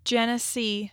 (JEN-ə-SĒ)